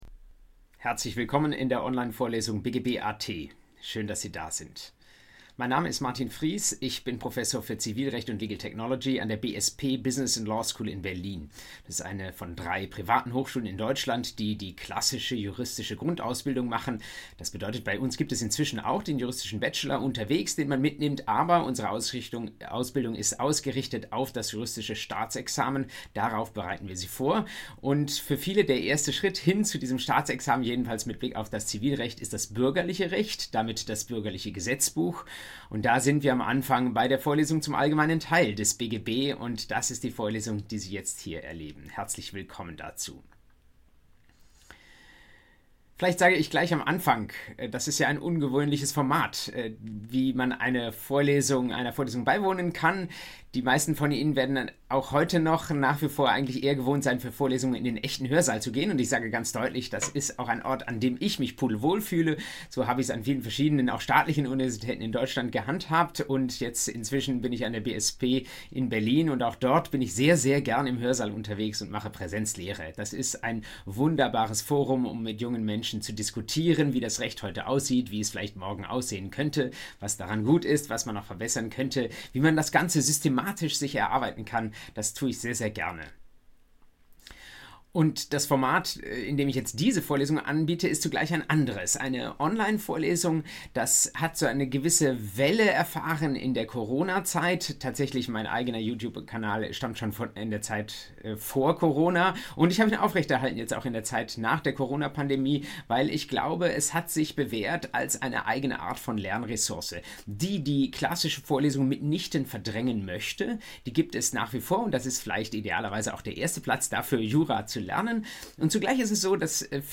BGB AT Einheit 1: Methodische Einführung ~ Vorlesung BGB AT Podcast